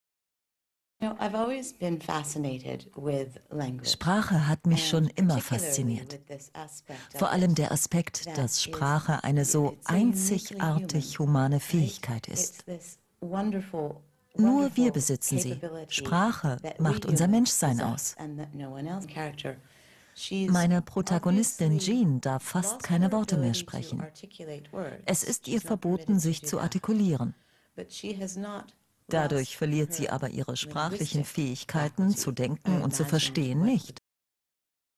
sehr variabel, markant
Mittel plus (35-65)
Doku